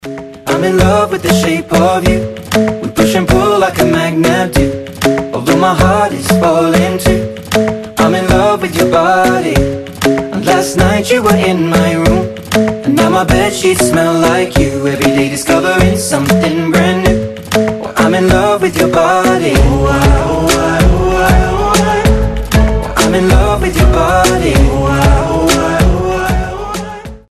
• Качество: 256, Stereo
поп
vocal
Indie